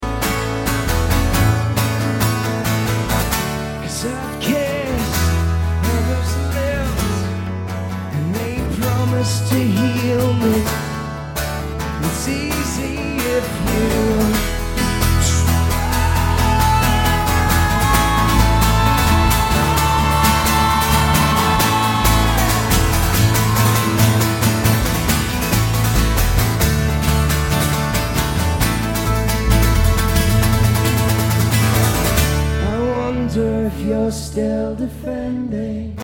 seattle, on this last run. that scream made me woozy!